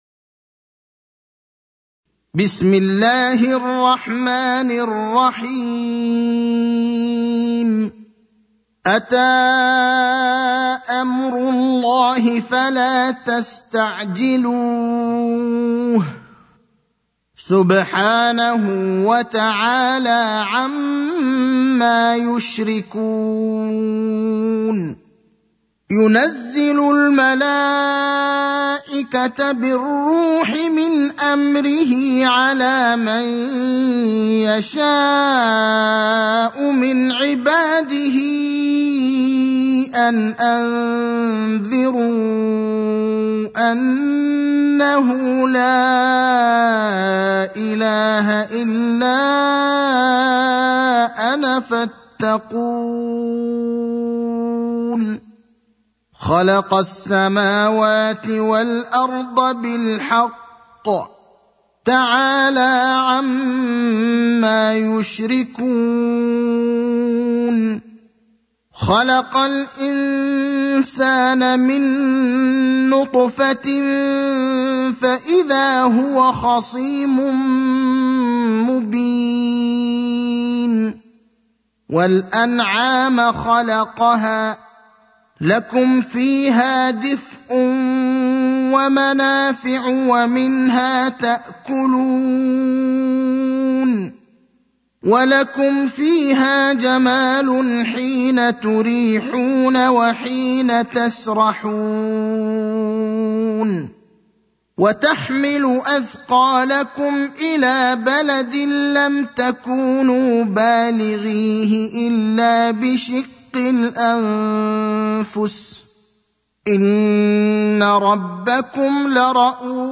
المكان: المسجد النبوي الشيخ: فضيلة الشيخ إبراهيم الأخضر فضيلة الشيخ إبراهيم الأخضر النحل (16)